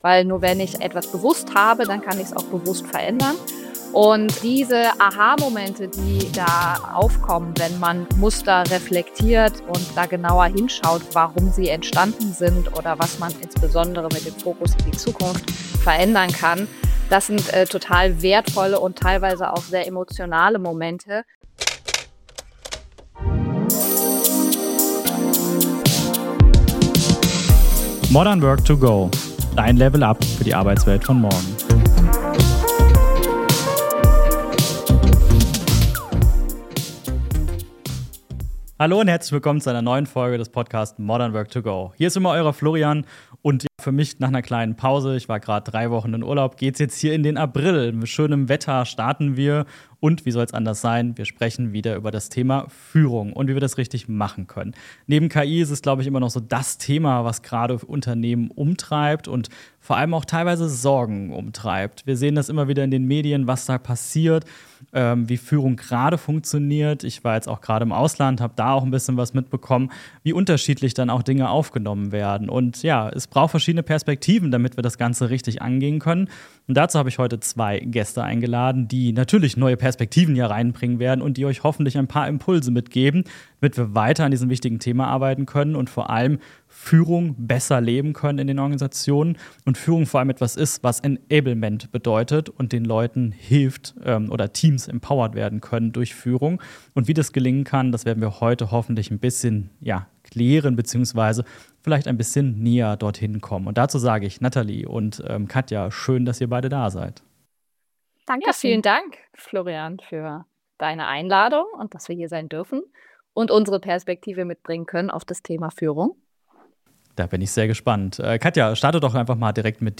#102 Wie kann Führung Teams wirklich empowern? - Interview